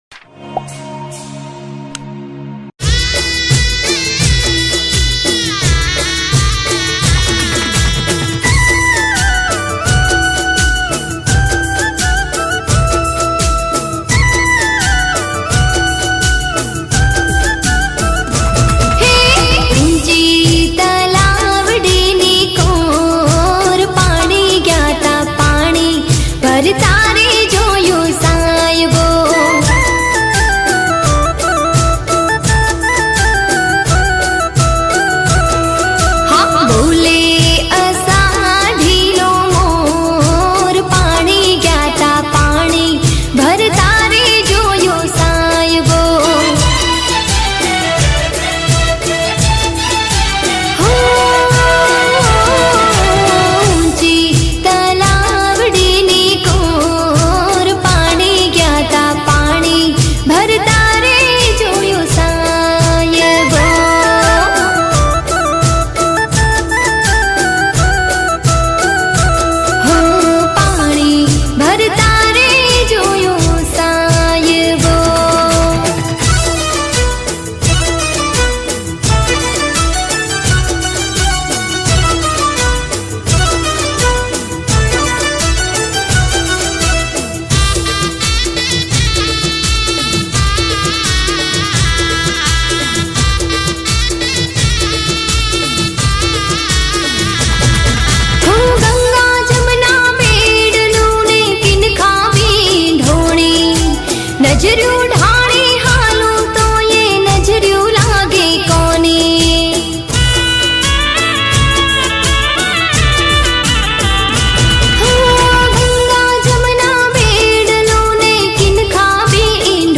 ગીત સંગીત ગરબા - Garba